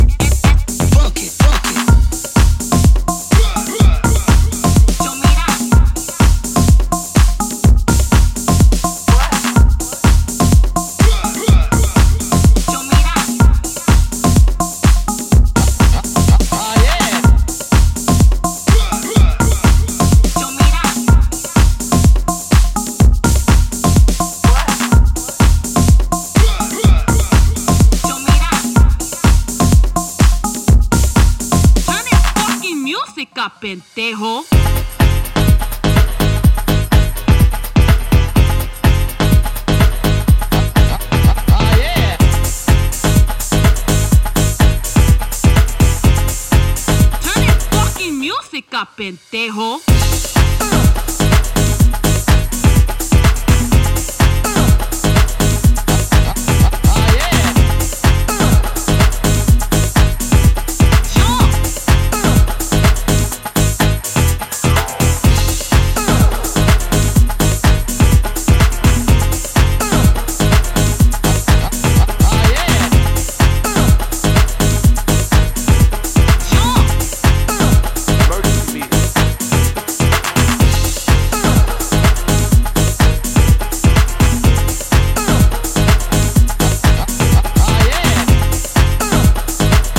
軽快なパーカッションとクラシカルなリフで、しっかり盛り上げてくれそうですね！
ジャンル(スタイル) HOUSE / DISCO HOUSE